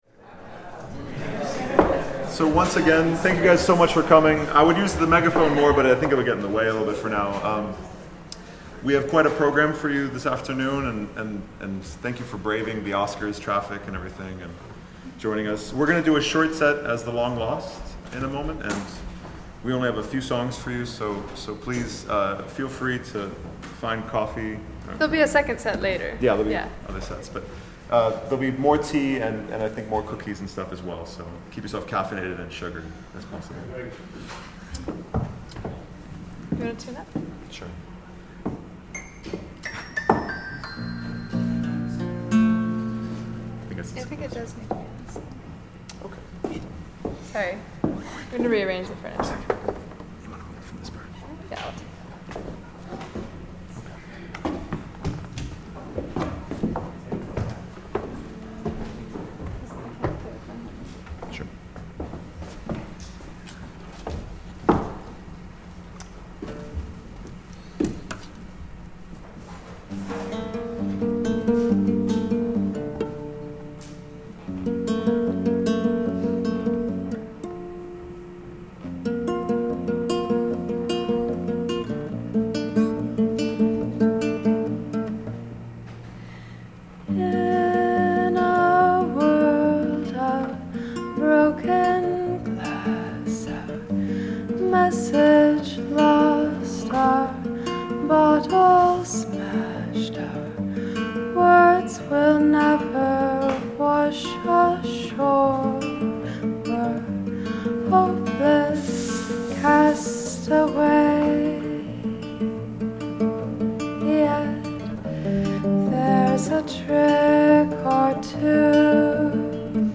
Folk Psych